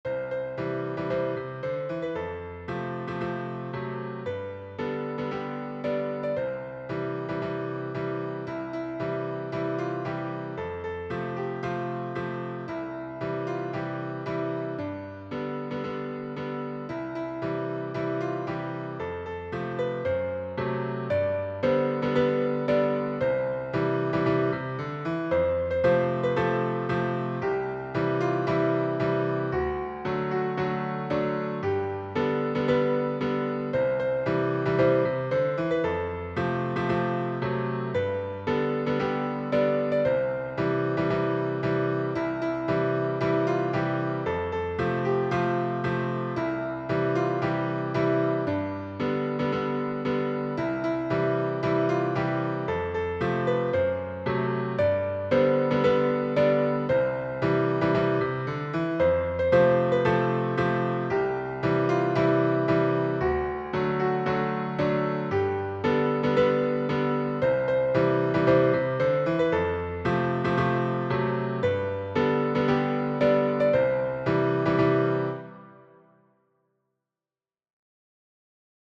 교가.mp3